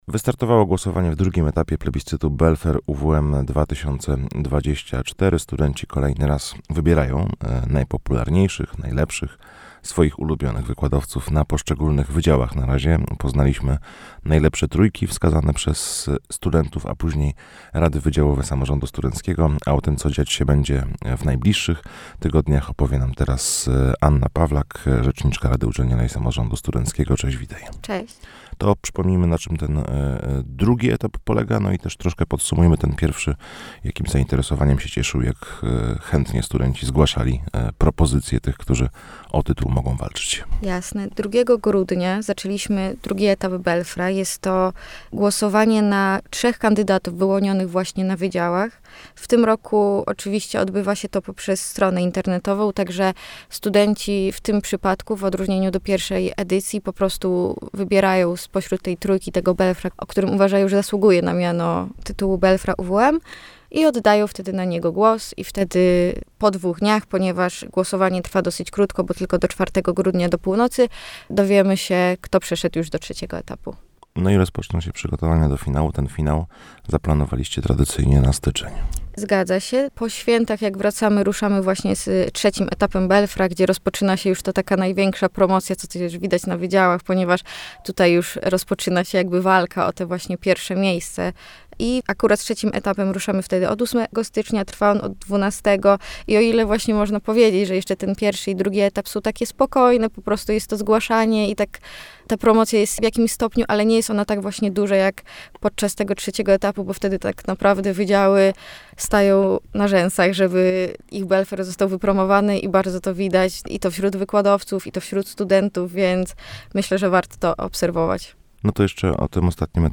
w naszym studiu